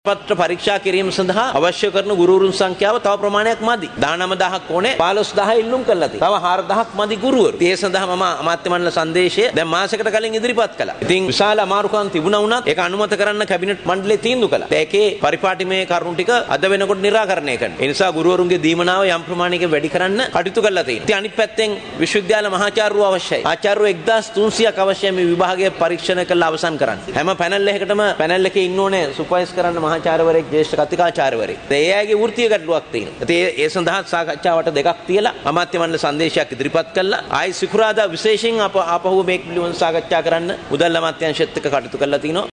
අධ්‍යාපන අමාත්‍යවරයාගෙන් ප්‍රකාශයක්
අද පාර්ලිමේන්තුවේදී ප්‍රකාශයක් සිදු කරමින් අමාත්‍යවරයා කියා සිටියේ පිළිතුරු පත්‍ර ඇගයීම සදහා 19 000ක ගුරුවරුන් ප්‍රමාණයක් අවශ්‍ය වුවද ඉල්ලුම් කර ඇත්තේ ගුරුවරුන් 15 000ක ප්‍රමාණයක් බවයි.